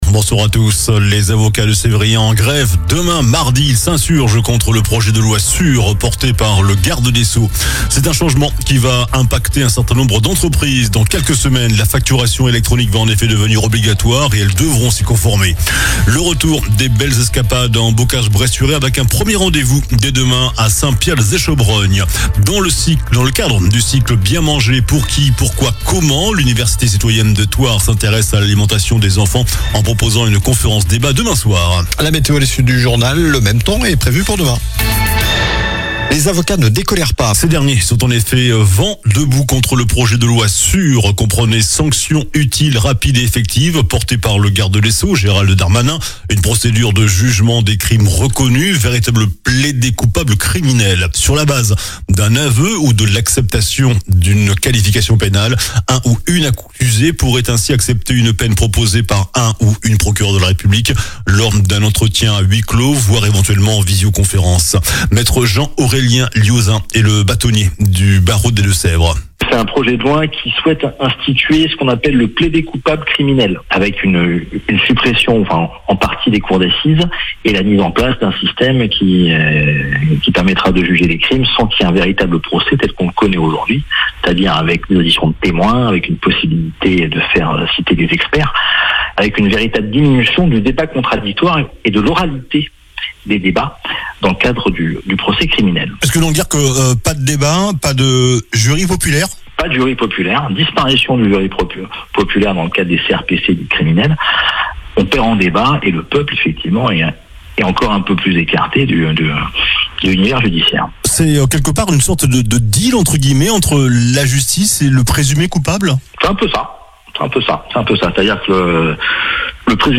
Journal du lundi 20 avril (soir)